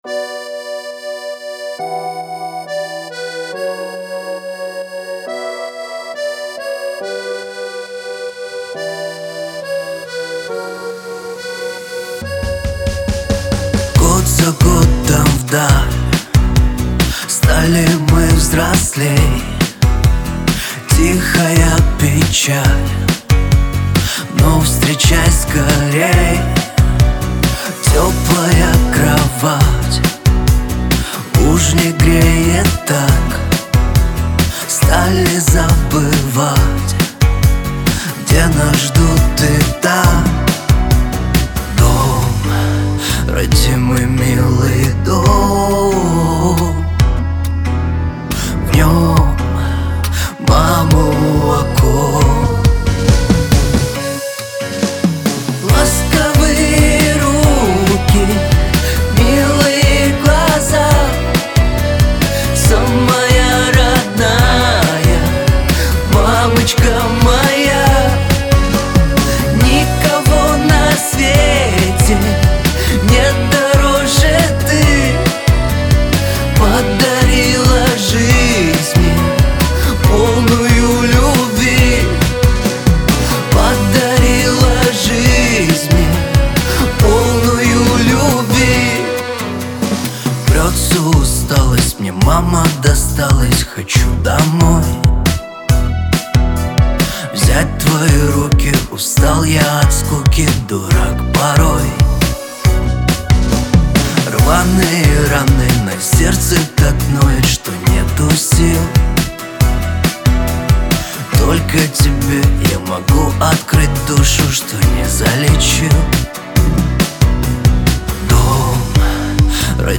эстрада , грусть